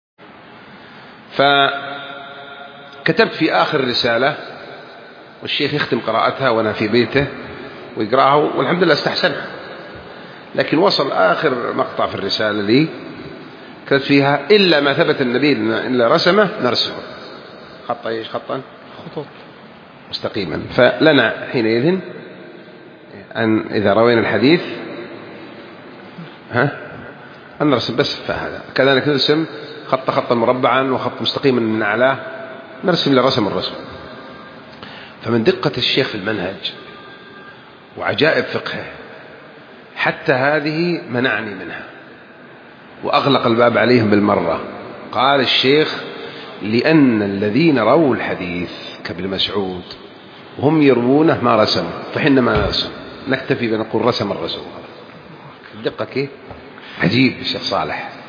285 [ درر قحطانية ] - من عجائب دقة فقه العلامة صالح الفوزان في المنهج { كلمة } .